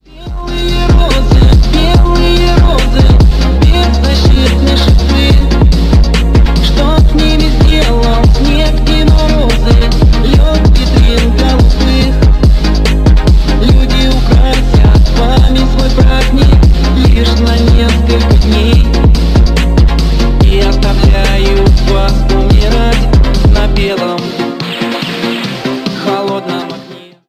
Ремикс
громкие